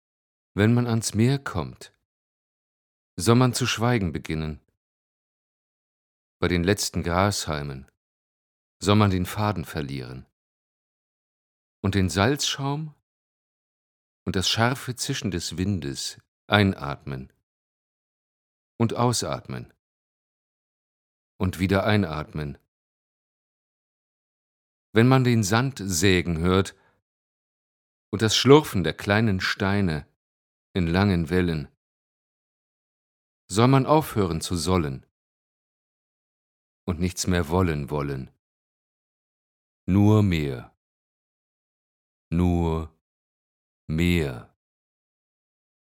Stimmproben
Lyrik - beruhigend / echt